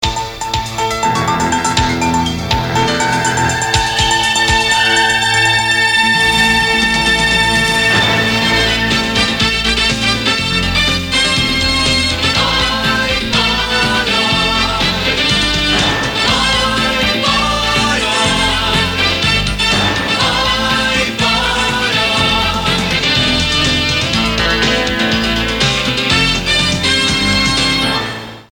Indicatiu cantat del programa